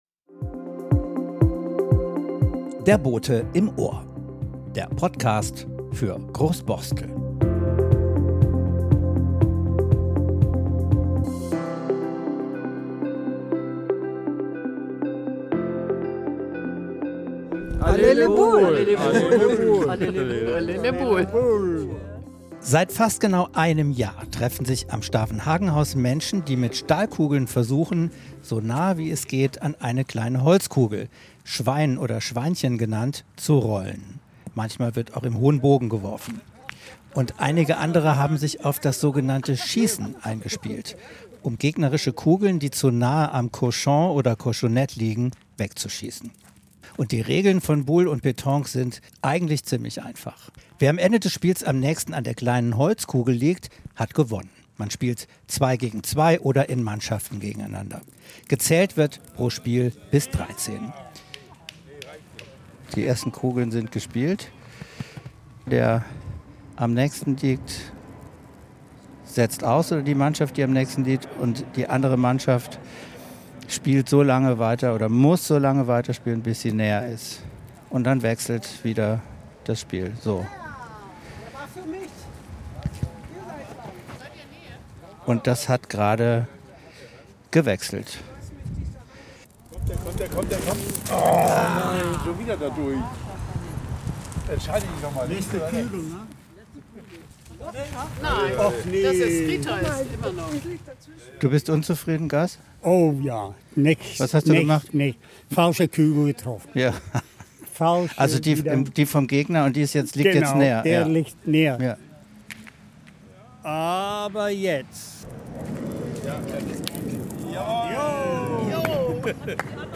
#46 Borstel-Boule: Ein Nachmittag auf der Boulebahn am Stavenhagenhaus! ~ Der Bote im Ohr Podcast
Beschreibung vor 1 Jahr Dieser Podcast nimmt Euch mit auf die Boule-Bahn am Stavenhagenhaus!